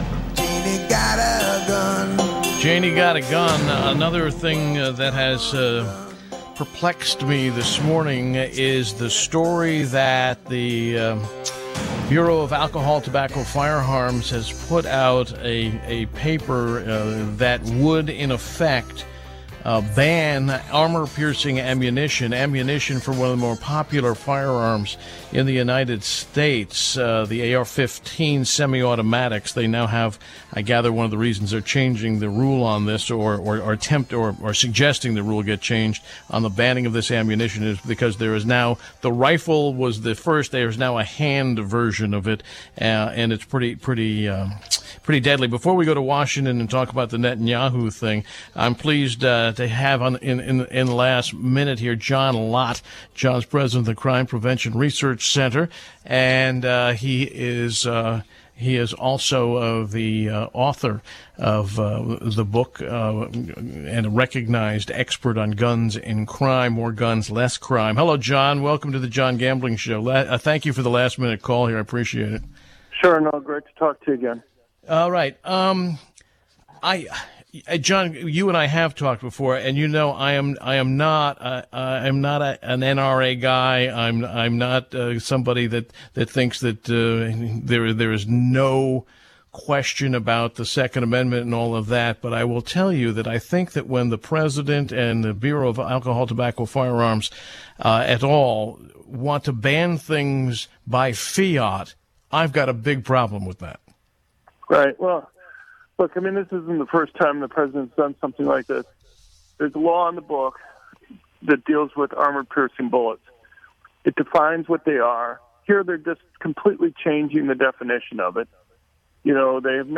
John Lott talked to John Gambling on Friday about Obama’s new proposed rules. The interview was on Friday, February 27, 2015 from 11:20 to 11:30 AM.